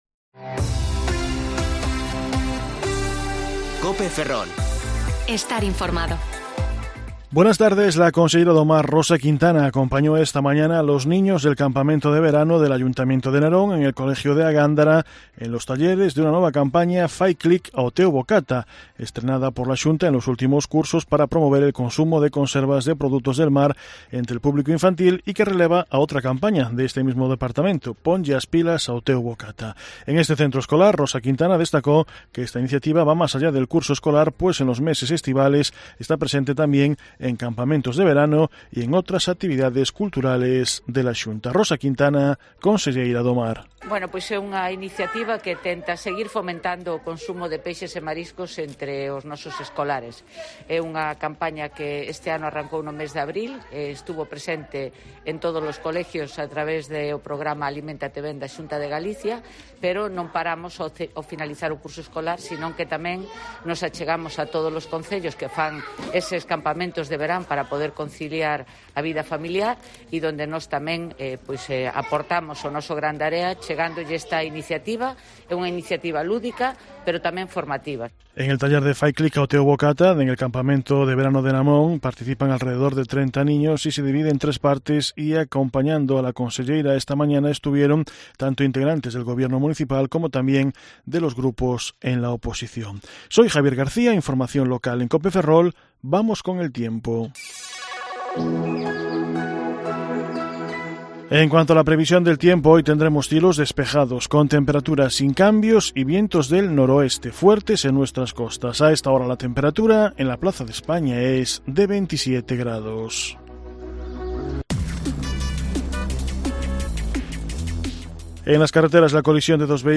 Informativo Mediodía COPE Ferrol 25/8/2021 (De 14,20 a 14,30 horas)